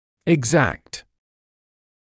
[ɪg’zækt][иг’зэкт]точный; аккуратный